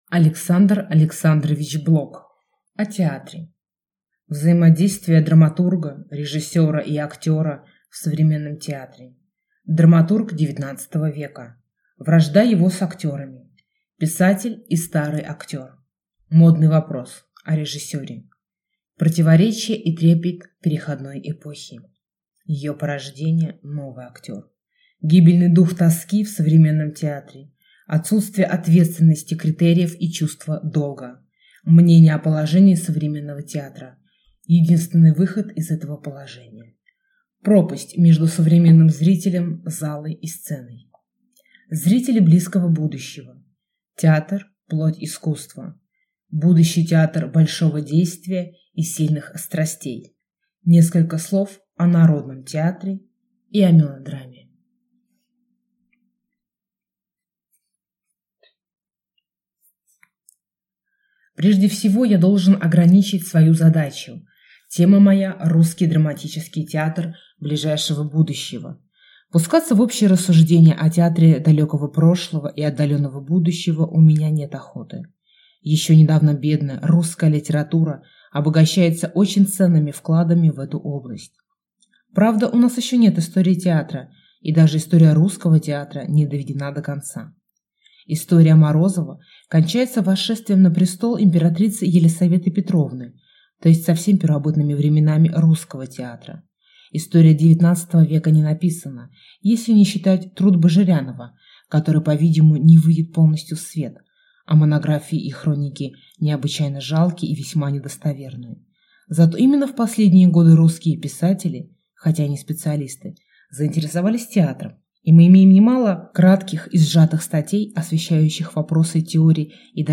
Аудиокнига О театре | Библиотека аудиокниг
Прослушать и бесплатно скачать фрагмент аудиокниги